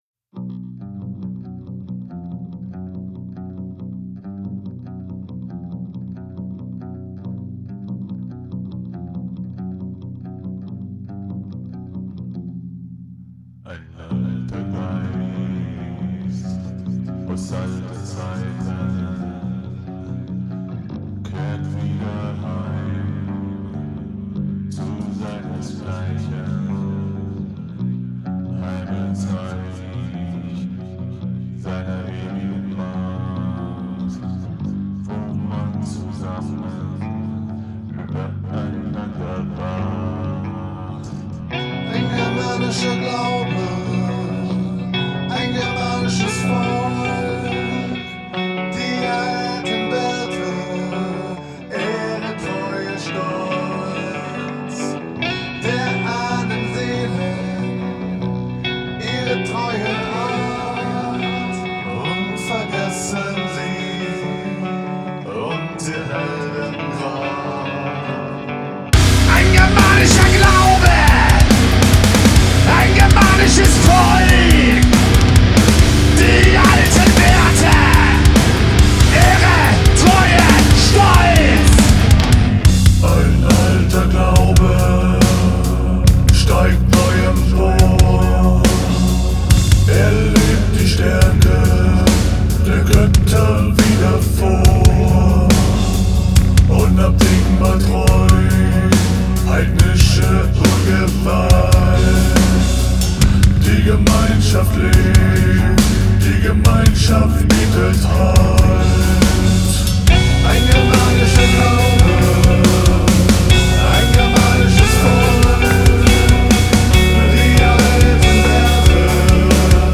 teils episch anmutenden Metal-Rock-Hybriden
Studioalbum